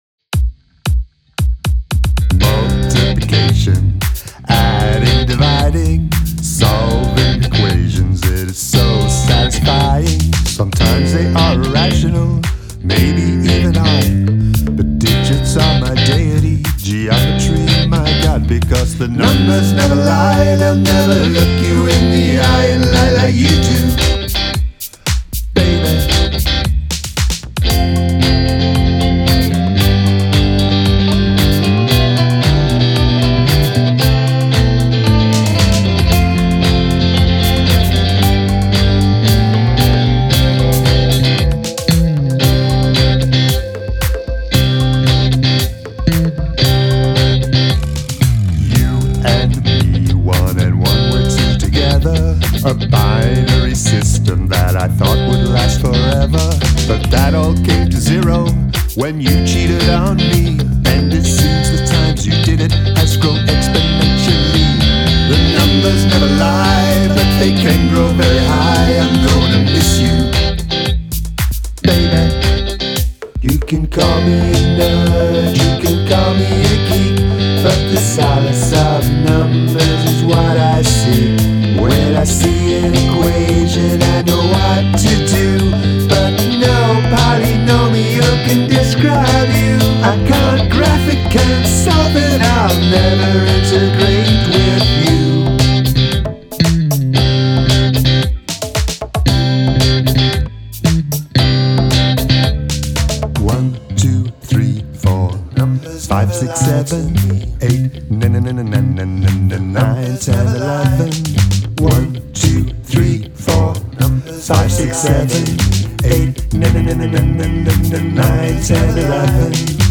Use the Royal Road chord progression
Bobbing along. The bass is fun.